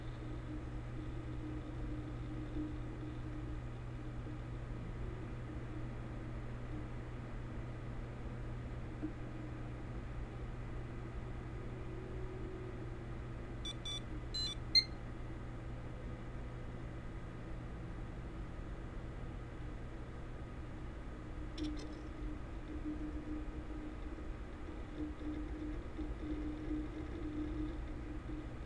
computerloop1.wav